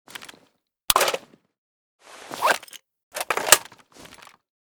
m82_reload.ogg